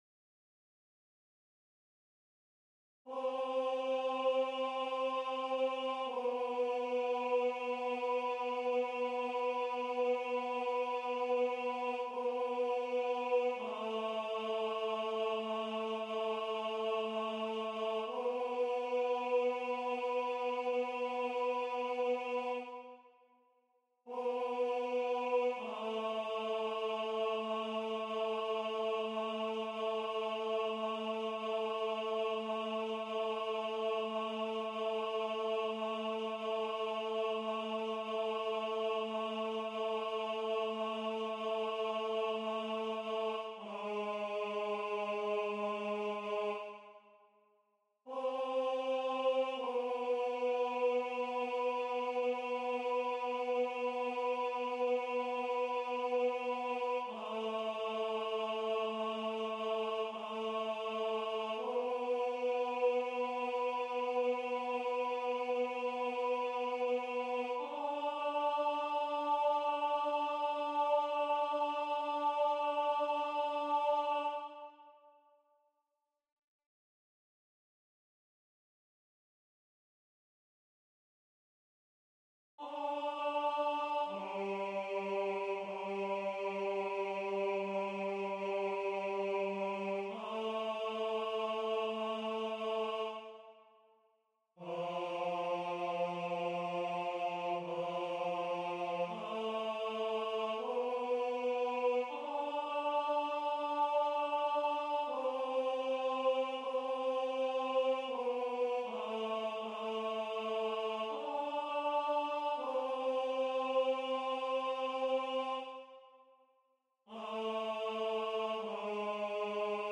- Œuvre pour chœur à 6 voix mixtes (SAATBB) a capella
MP3 rendu voix synth.
Tenore